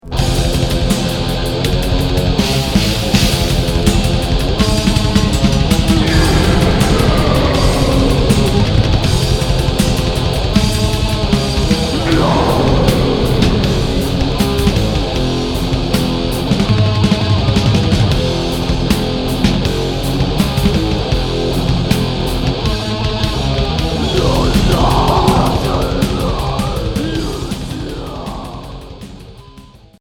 Doom métal